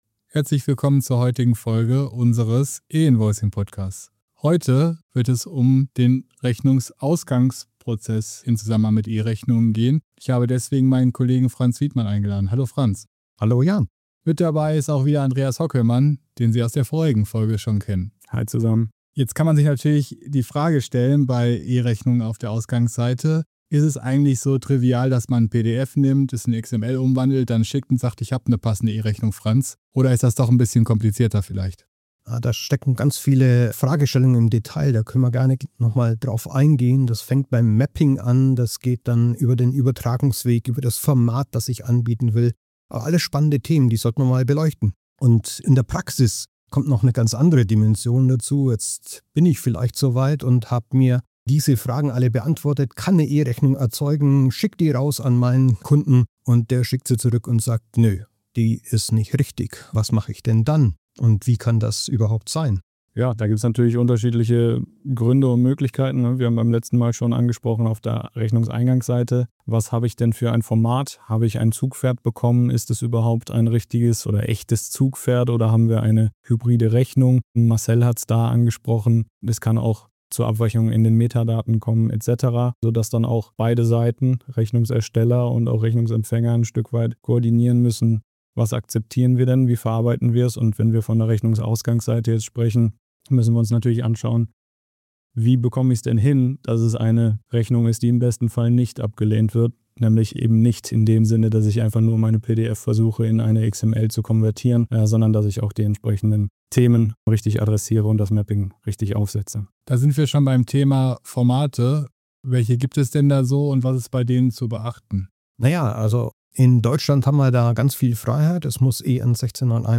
Die Podcast-Reihe „Einfach E-Rechnung“ beleuchtet im Rahmen von kurzweiligen Expertengesprächen neben den aktuellen rechtlichen Entwicklungen und Rahmenbedingungen in Deutschland auch viele technische und prozessuale Fragestellungen im Rechnungseingangs- und Rechnungsausgangsprozess.